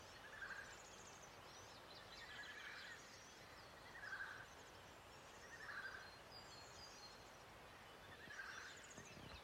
Oiseau inconnu - chant
Savoie, 450 m d'altitude, campagne, pr�s des maisons On entend plusieurs oiseaux sur mon fichier ( m�sange, pinson) , celui � identifier fait : "tu tuuu" .